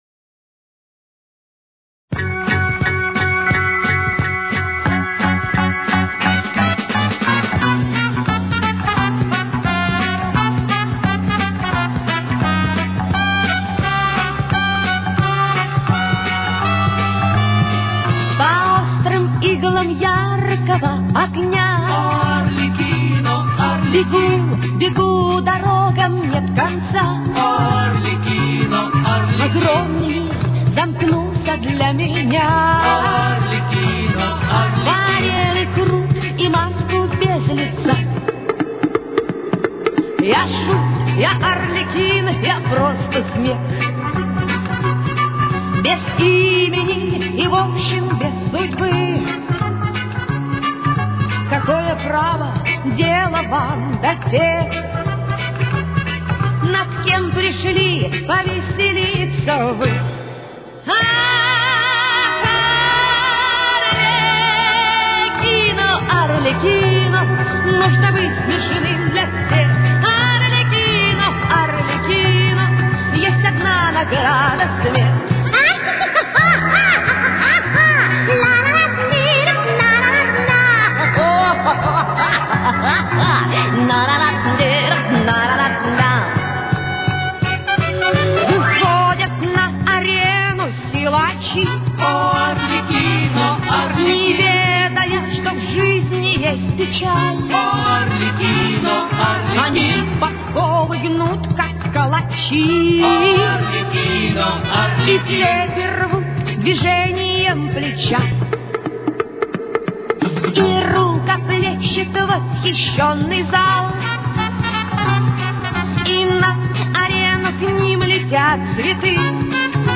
с очень низким качеством (16 – 32 кБит/с)
Темп: 89.